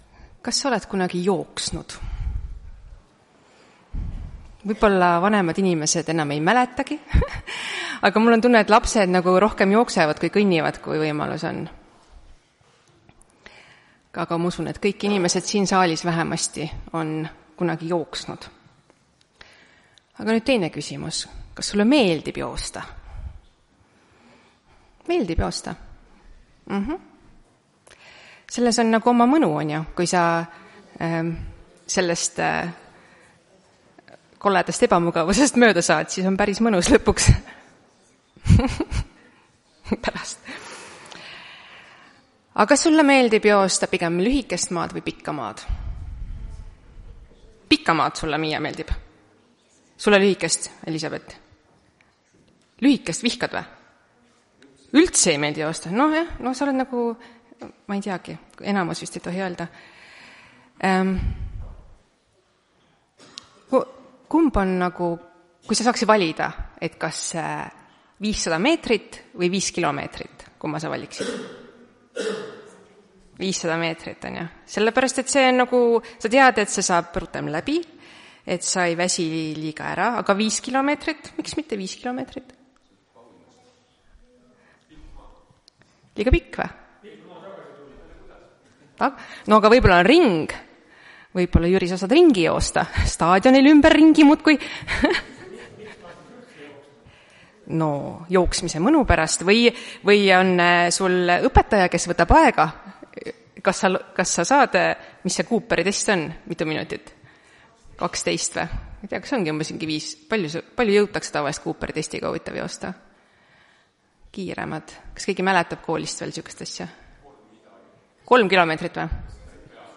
Tartu adventkoguduse 28.02.2026 hommikuse teenistuse jutluse helisalvestis.